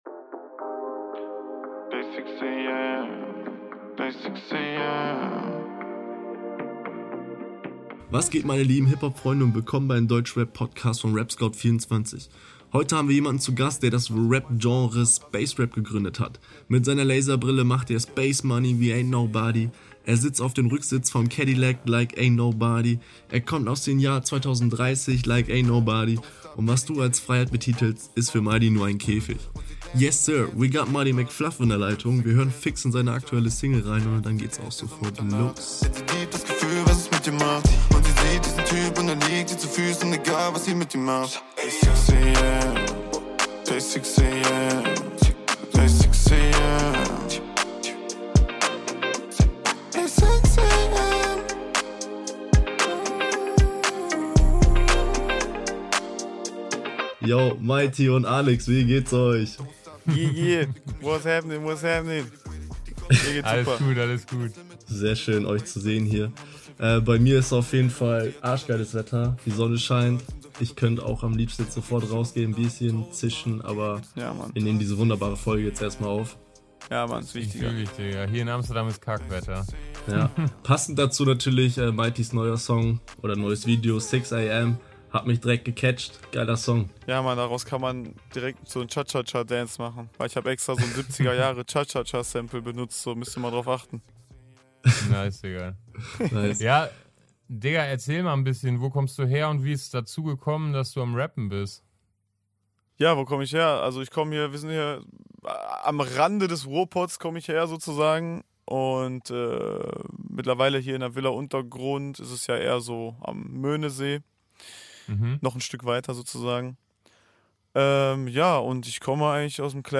Letzte Episode #19 Interview